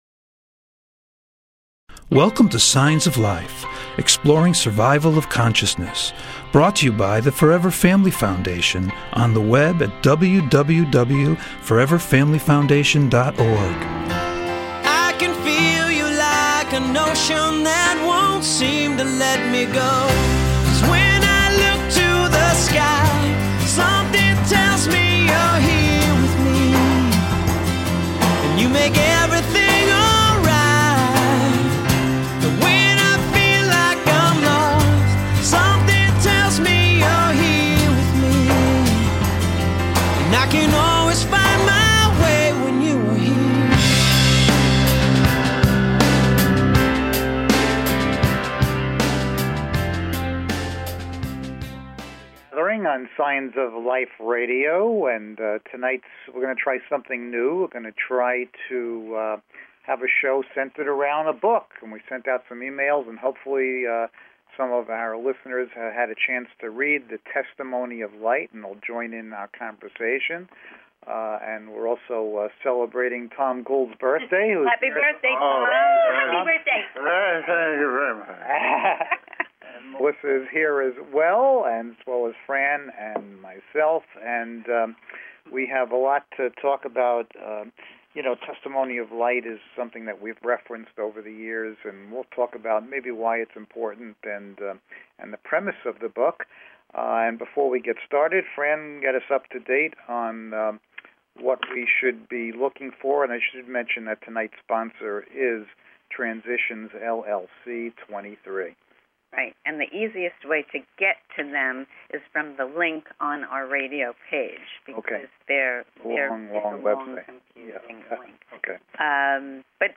Gathering Discussion Show: Topic - Book Discussion of Testimony of Light